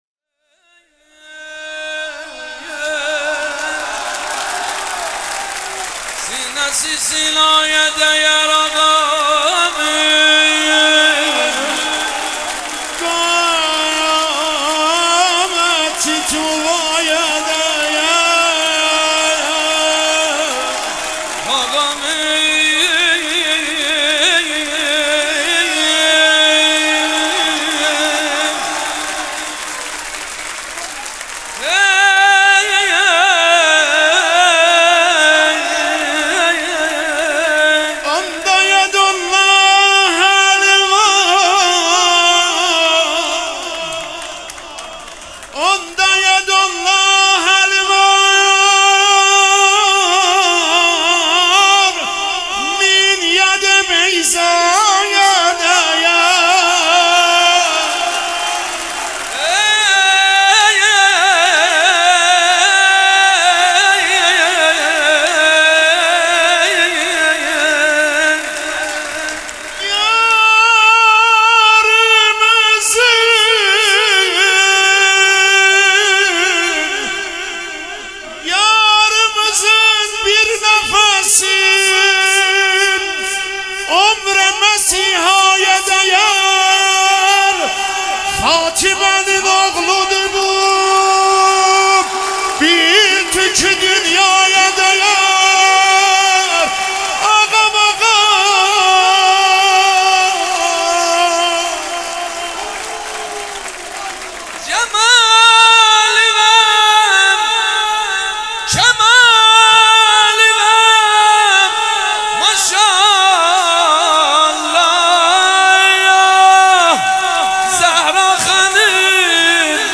نغمه خوانی
جشن نیمه شعبان